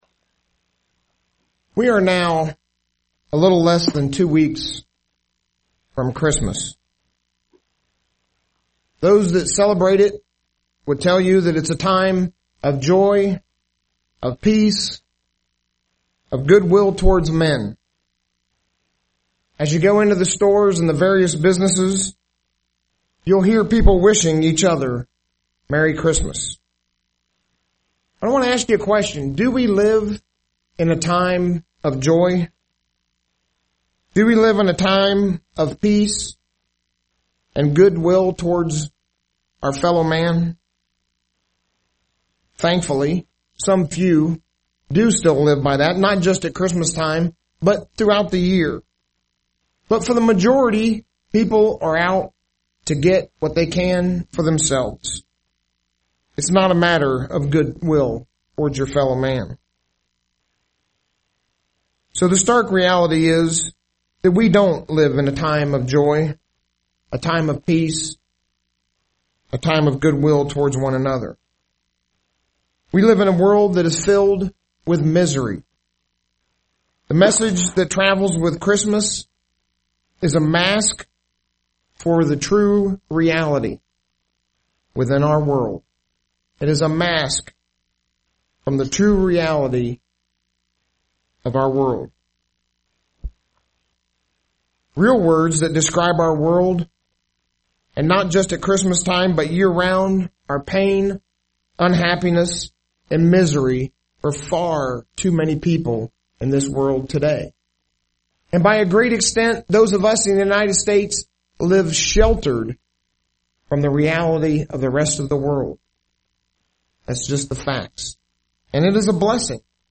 UCG Sermon Notes Notes: Do we live in time of joy, peace, and goodwill?